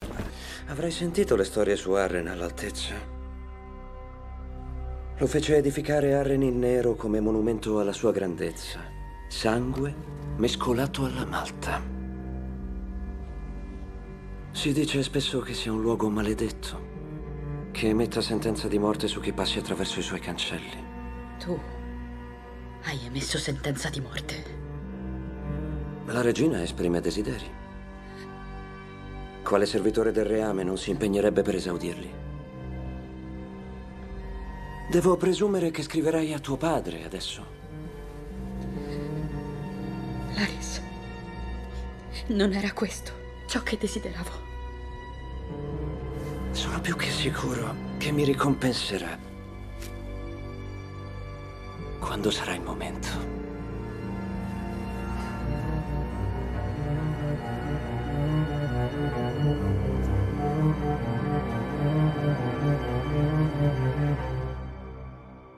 nel telefilm "House of the Dragon", in cui doppia Matthew Needham.